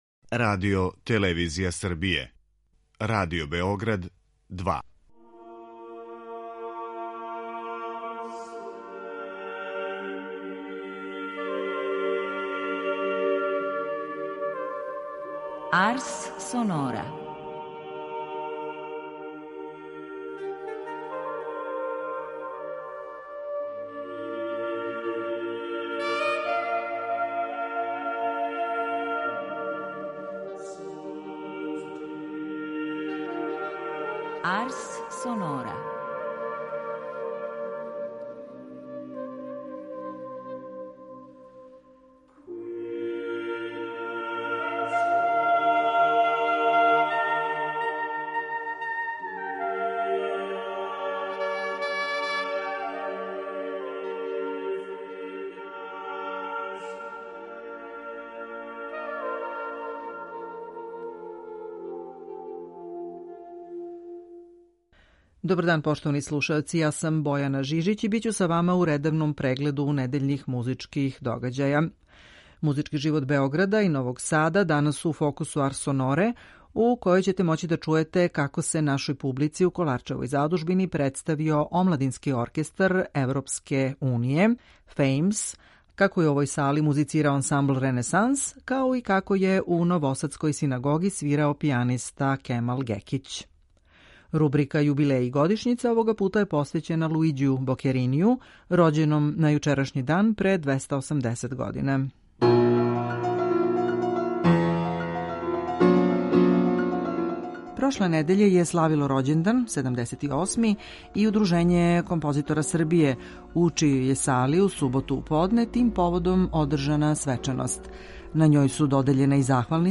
Известићемо вас и о томе како је у овој сали музицирао ансамбл Ренесанс, као и како је у Новосадској синагоги свирао пијаниста Кемал Гекић.